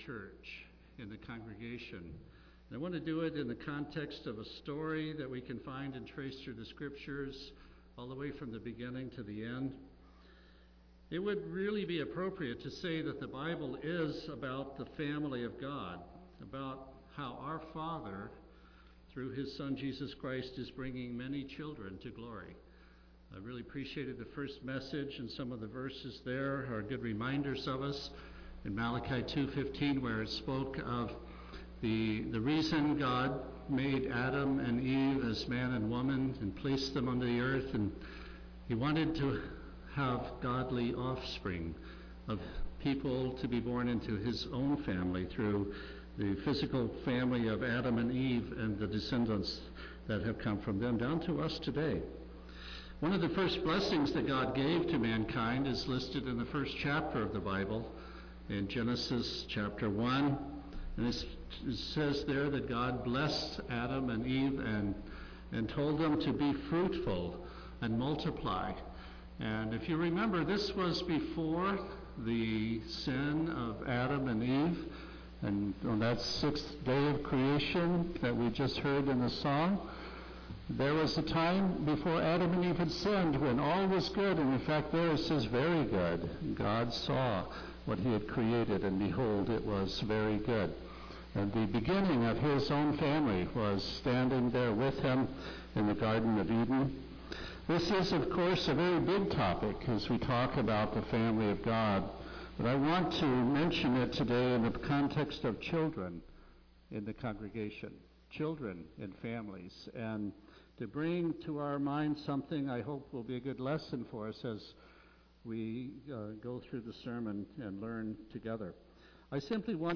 Given in Tacoma, WA
UCG Sermon Studying the bible?